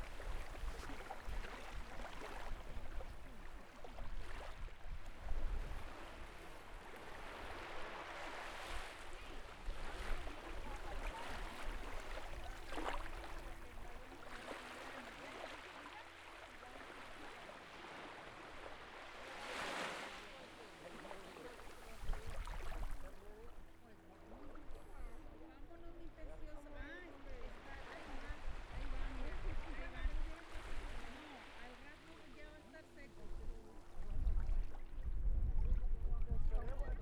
I took off my shoes and put the Zoom H4n Pro real close to the water for this one.
Lake Water.wav